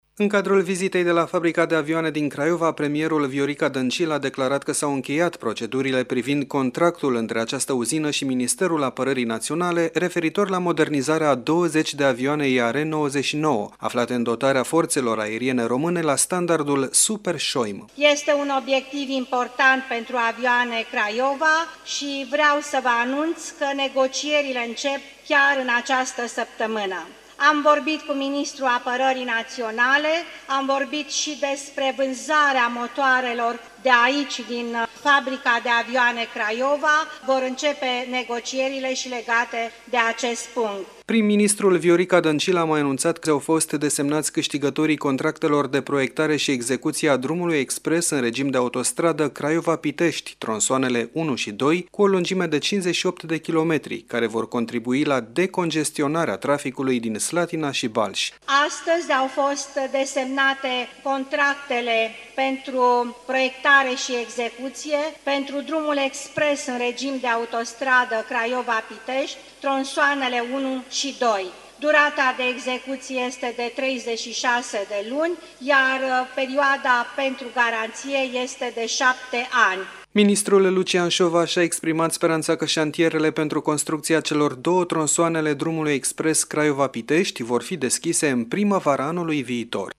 Corespondentul RRA